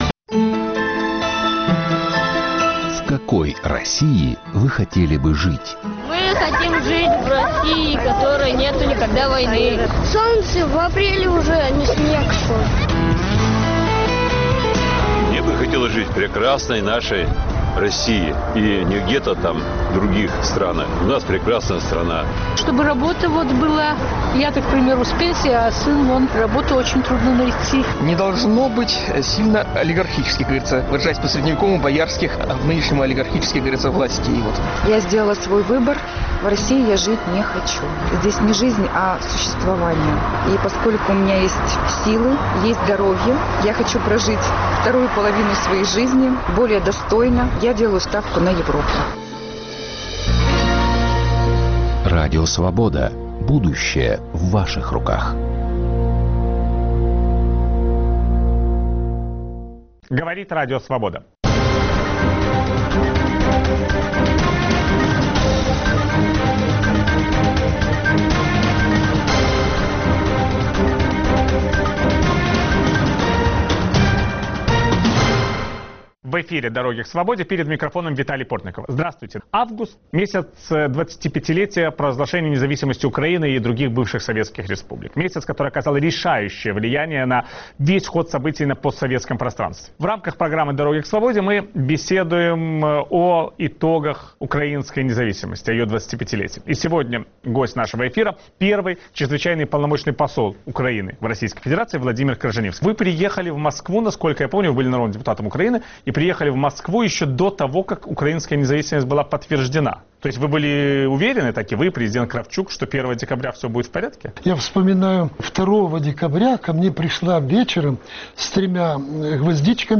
Виталий Портников беседует с первым послом Украины в Российской Федерации Владимиром Крыжанивским